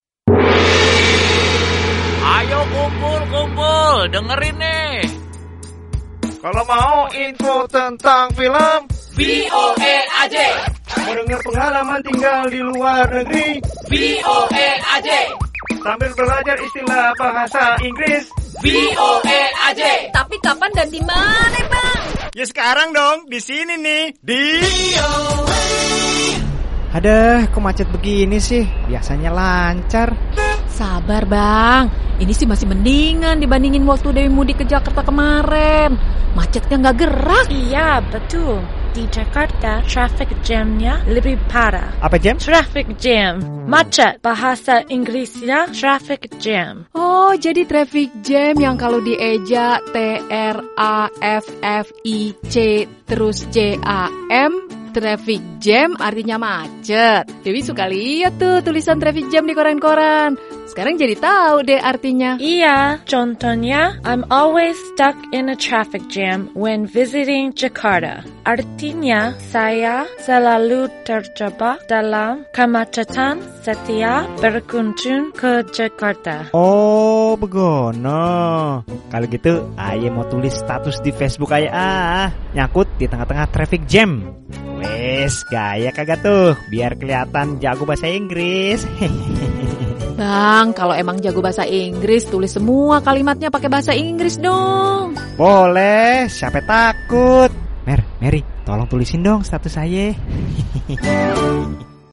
Kali ini ada kata Traffic Jam yang artinya Macet. Simak dalam percakapan berikut tentang bagaimana pengucapan dan penggunaannya dalam kalimat sehari-hari.